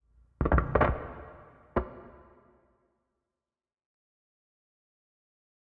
Minecraft Version Minecraft Version latest Latest Release | Latest Snapshot latest / assets / minecraft / sounds / ambient / nether / warped_forest / mood7.ogg Compare With Compare With Latest Release | Latest Snapshot